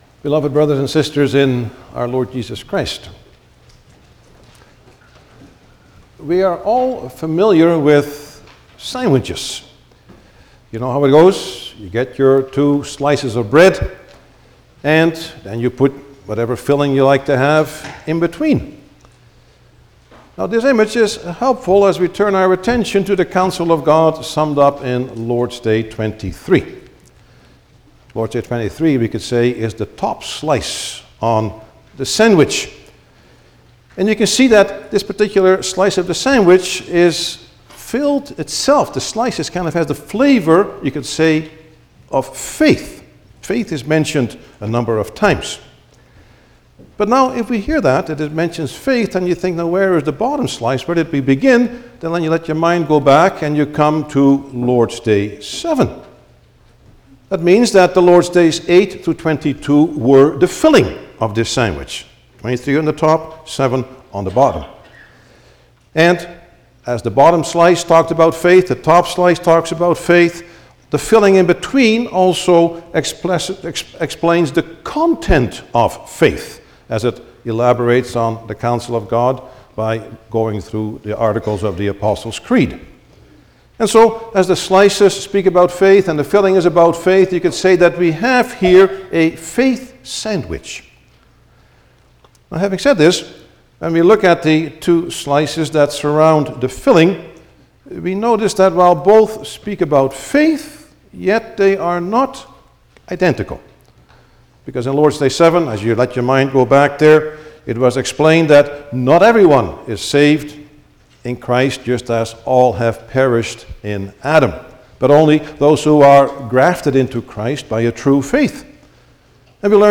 Passage: God's Word as summarized in the Heidelberg Catechism Lord's Day 23 Service Type: Sunday afternoon
08-Sermon.mp3